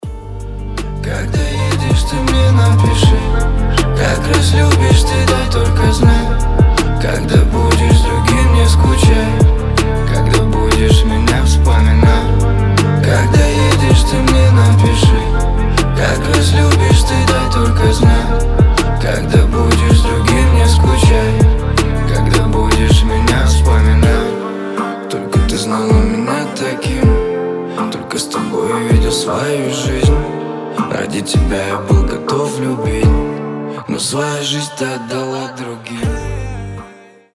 лирика
грустные
красивый мужской голос